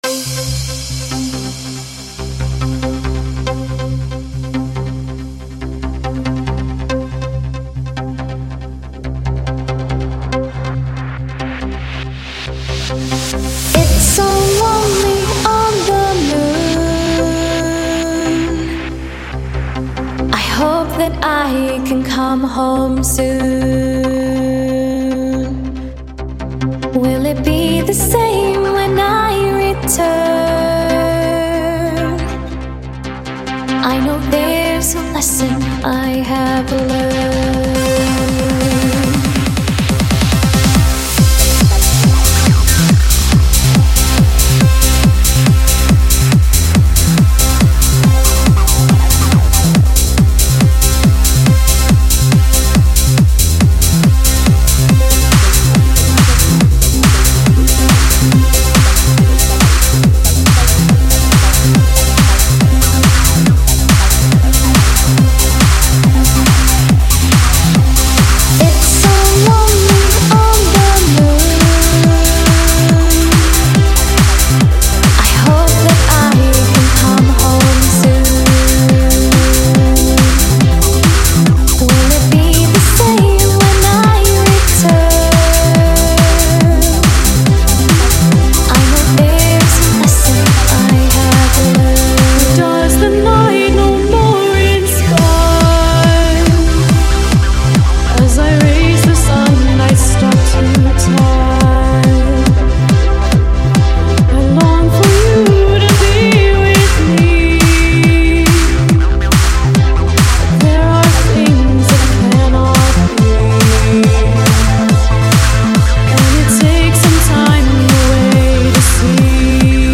psytrance basslines